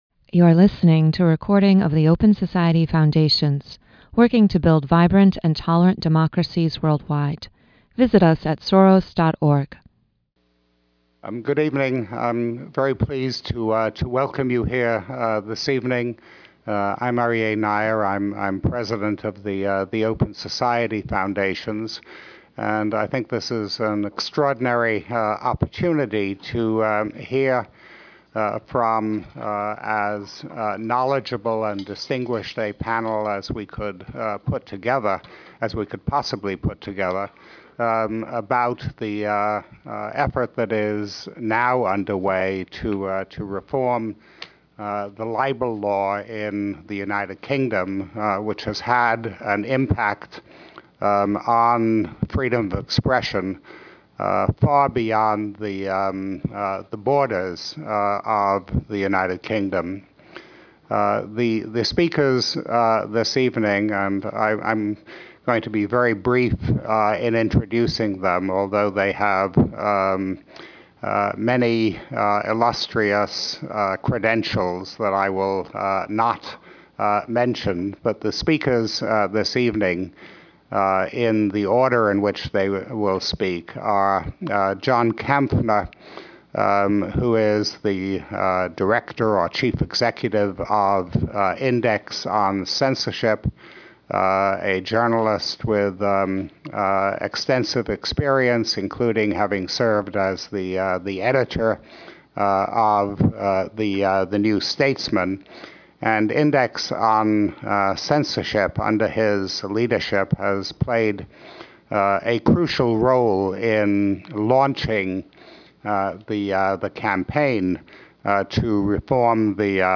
There is now a realistic prospect that UK libel law, which has a severe impact on freedom of expression worldwide, will be reformed. This event features a panel of experts discussing the possibilities for reform.
The moderator is Aryeh Neier, President of the Open Society Foundations.